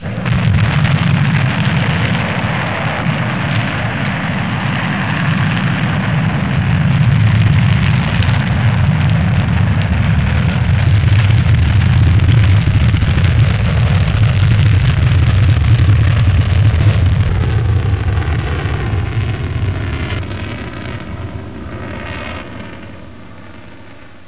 دانلود آهنگ طیاره 62 از افکت صوتی حمل و نقل
دانلود صدای طیاره 62 از ساعد نیوز با لینک مستقیم و کیفیت بالا
جلوه های صوتی